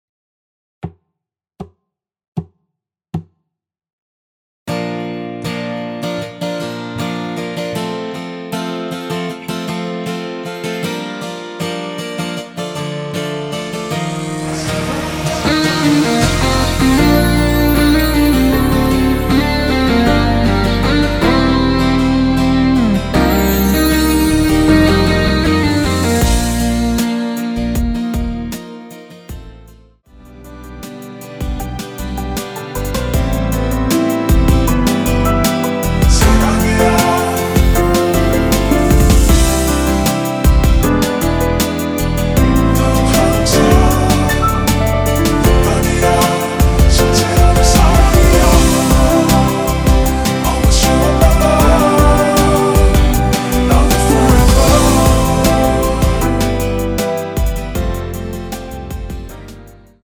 전주 없이 시작하는 곡이라서 노래하기 편하게 카운트 4박 넣었습니다.(미리듣기 확인)
원키에서(-6)내린 코러스 포함된 MR입니다.
Bb
앞부분30초, 뒷부분30초씩 편집해서 올려 드리고 있습니다.
중간에 음이 끈어지고 다시 나오는 이유는